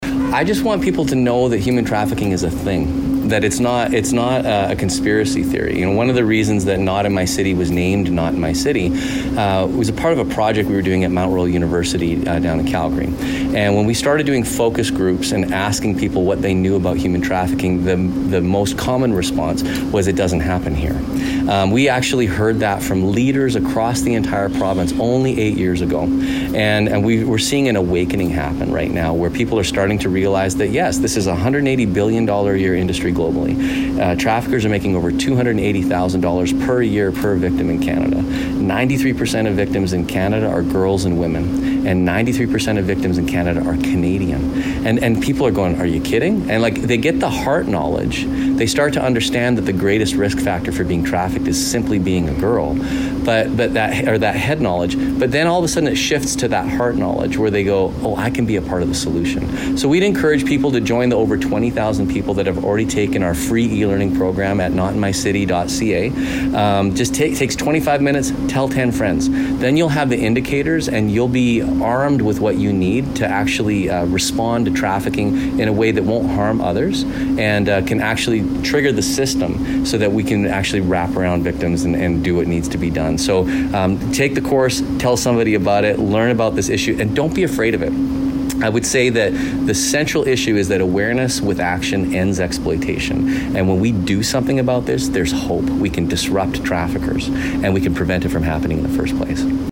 Here Brandt talks about how Not My City was created and why it needed to happen.